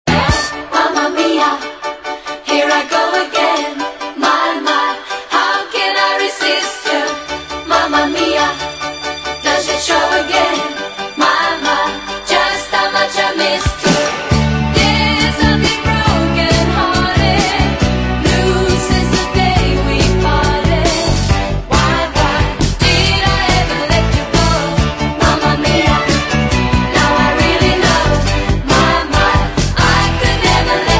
• Dance Ringtones